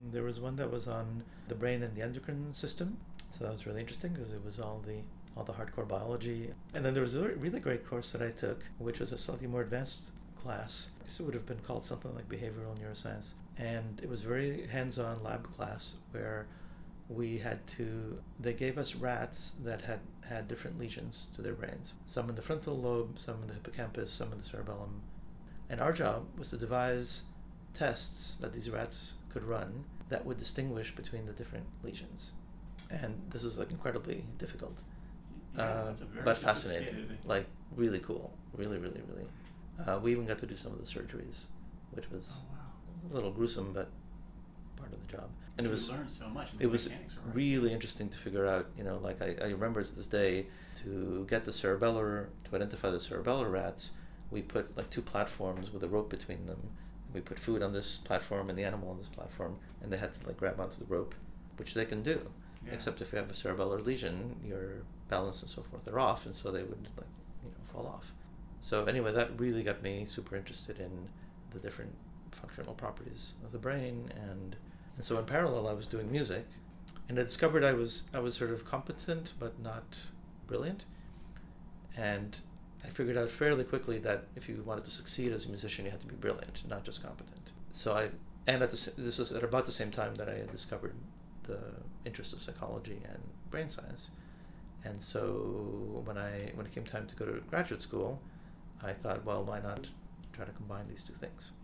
In the following clip Dr. Zatorre discusses the development of his interests in neuroscience and the development of his musical abilities while an undergrad student at Boston University.
Here’s a a great recollection from Dr. Zatorre about those inspiring undergrad courses: